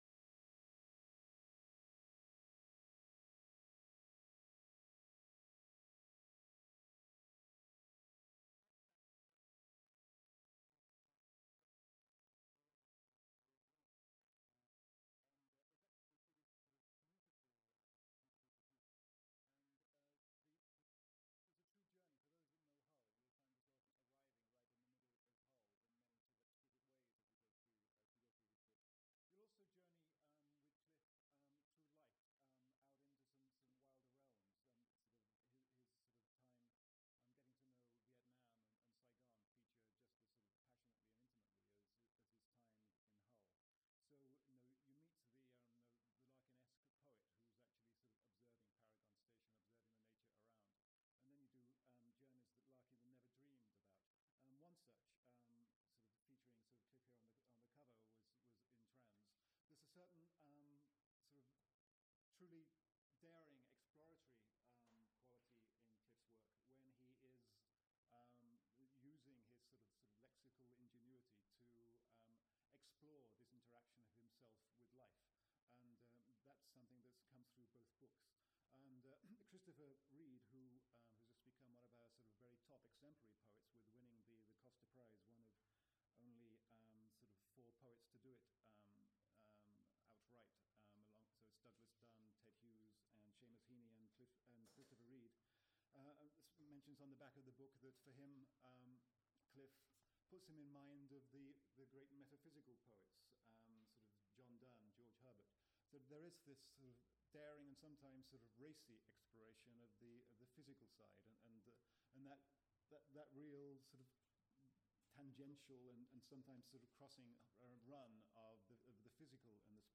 Recording of an event held 17th February 2010 at ArtLink, Hull.